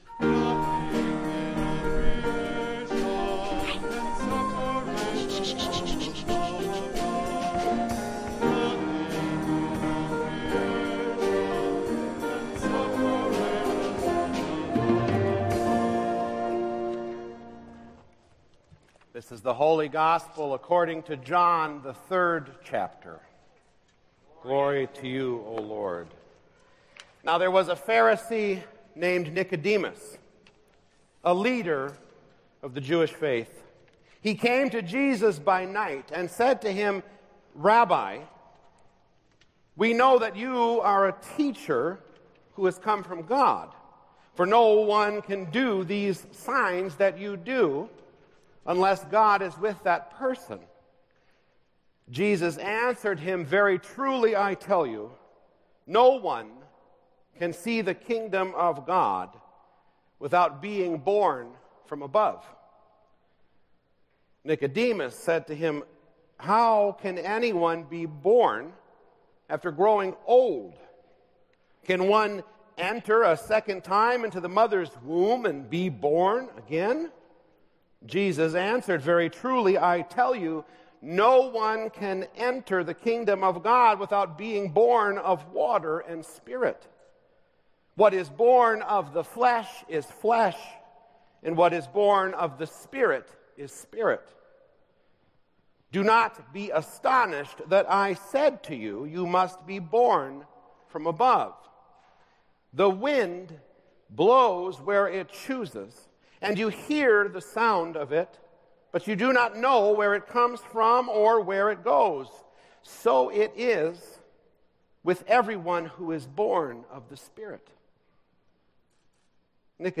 Worship Services | Christ The King Lutheran Church
March 5 Worship